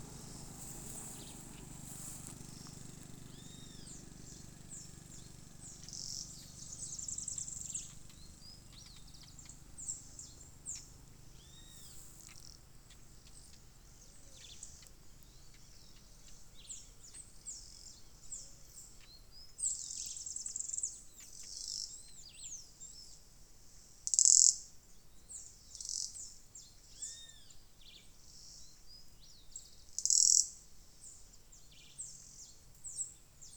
Juruviara (Vireo chivi)
Condição: Selvagem
Certeza: Gravado Vocal